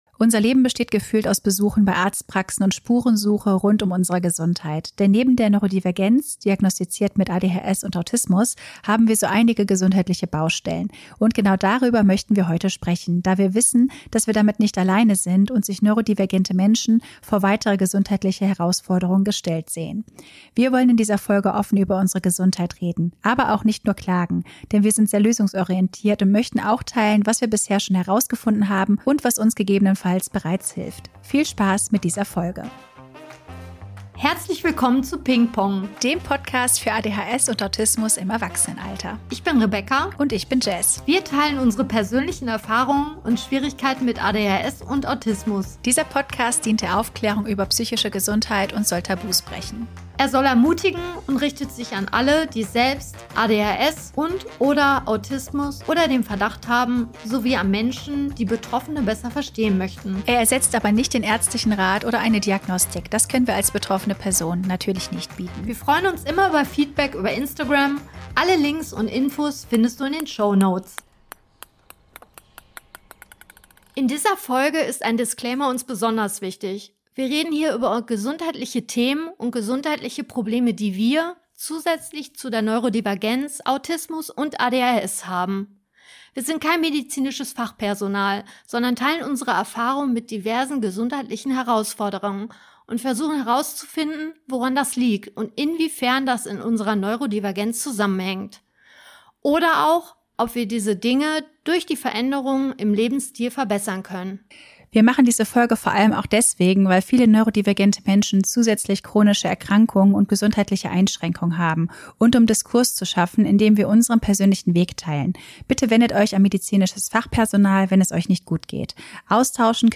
Hier wollen wir mit Klischees aufräumen, Vorurteile bekämpfen und unsere Erfahrungen als erwachsene AuDHSlerinnen mit euch teilen. Bei uns gibt es keine Tabus und kein Schamgefühl – nur ganz viele wilde Gedankensprünge und den ehrlichen Austausch zweier Freundinnen auf dem Weg zu mehr Selbsterkenntnis und Selbstakzeptanz.